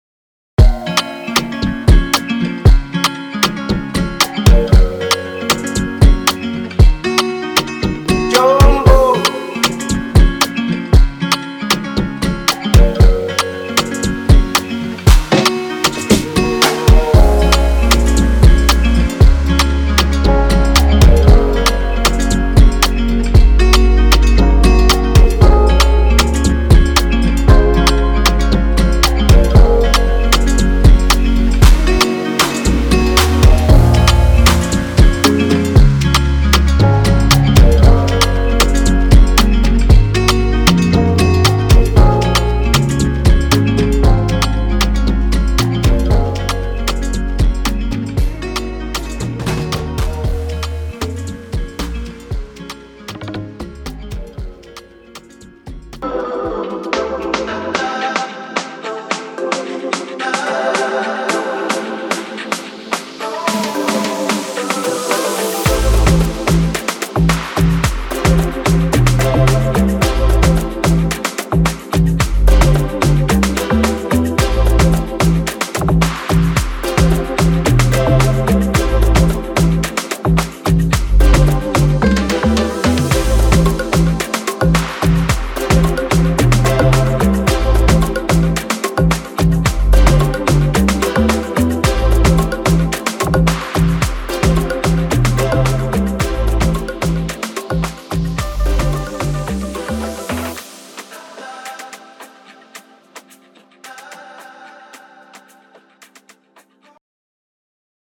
Check out the audio preview – Actual samples used from this percussion sample pack!